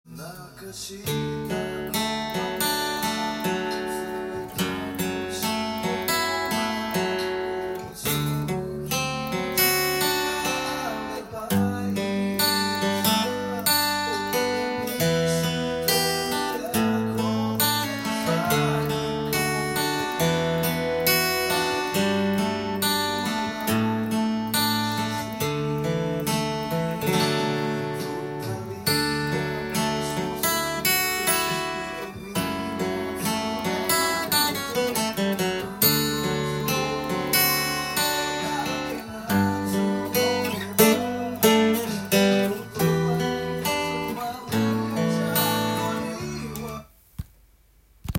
アルペジオtab譜
音源に合わせて譜面通り弾いてみました
2フレットにカポタストを装着すると譜面と同じ